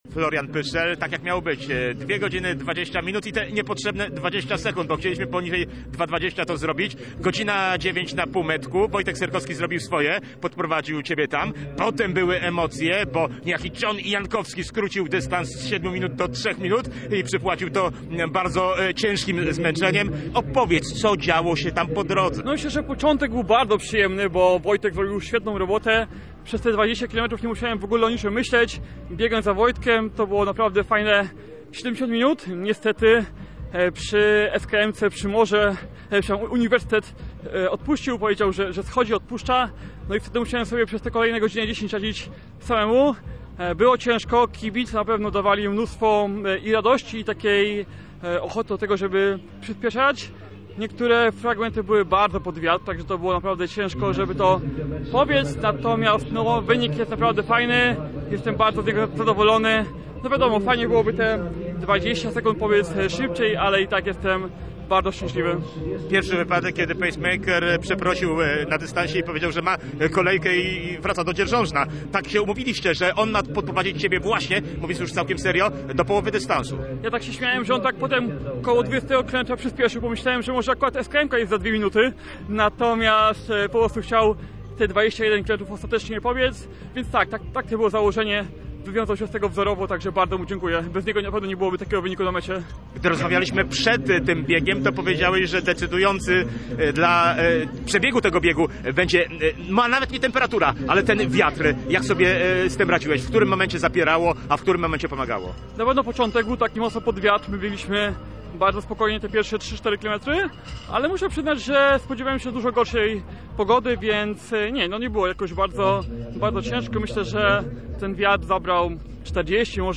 O godz. 14:45 na Polsat Plus Arenie rozpoczęła się ceremonia wręczenia nagród.